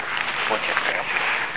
EVP (Electronic Voice Phenomena)
Some examples of EVP recordings (in wav format)